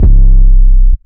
808s
11 808 -juice.wav